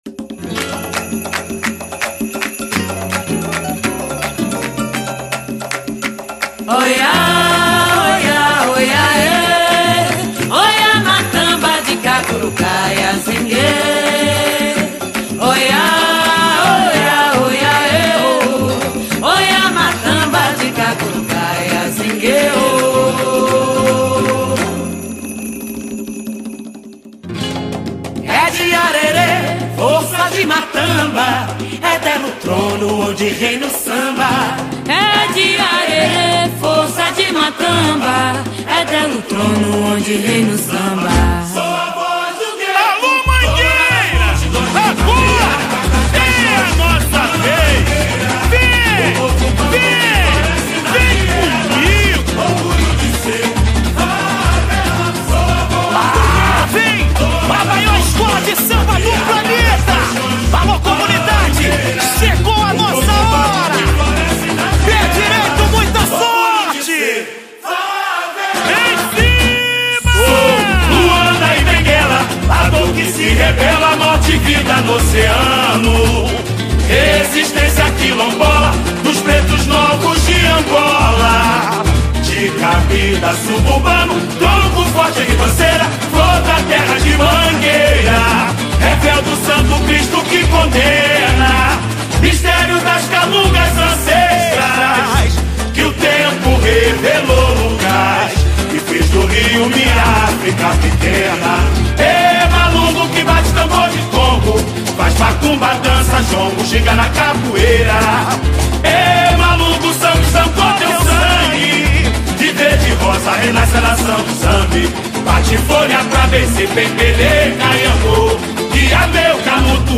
Samba Enredo 2025